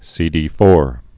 (sēdē-fôr)